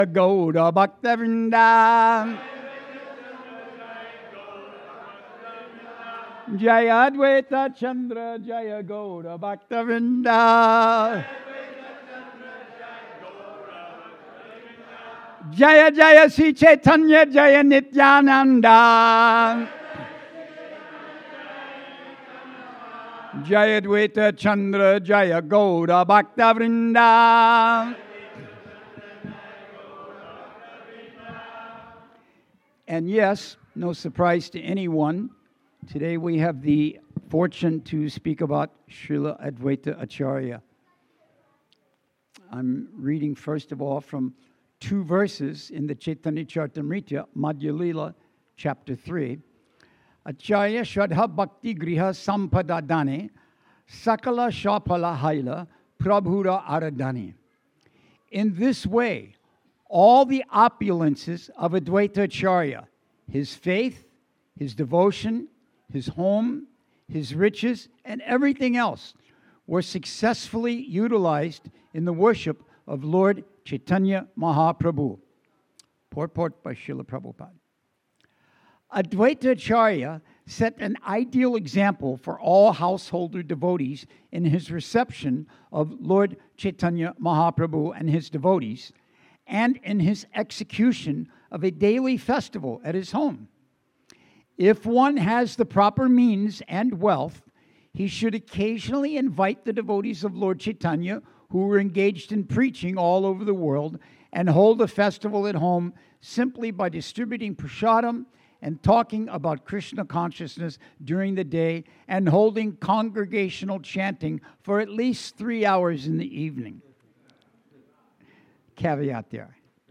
Sunday Lecture Appearance Day of Srila Advaita Acarya
at the Hare Krishna Temple in Alachua, Florida